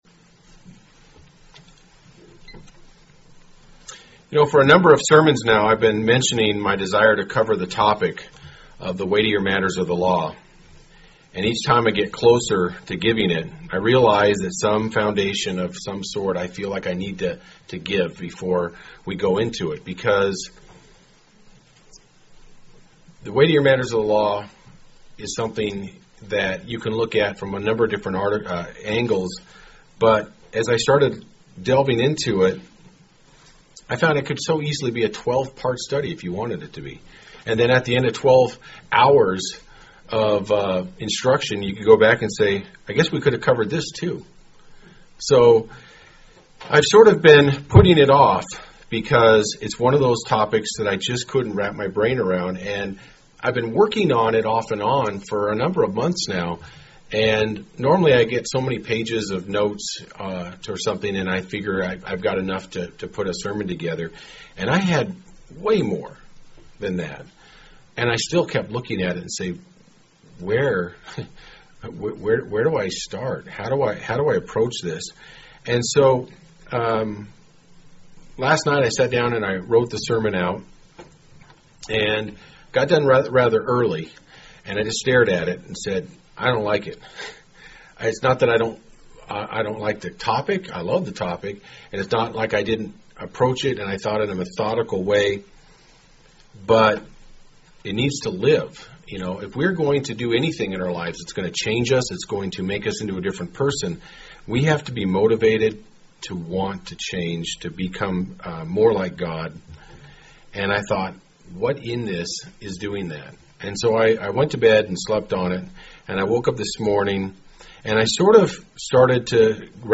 UCG Sermon Notes For a number of sermons I have made mention my desire to cover this topic of the weightier matters of the law.